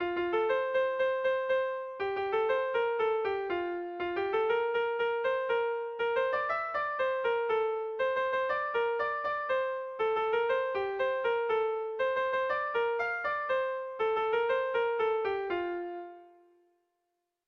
Sentimenduzkoa
ABDE